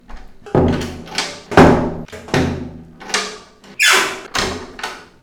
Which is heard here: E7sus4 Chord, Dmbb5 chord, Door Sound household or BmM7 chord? Door Sound household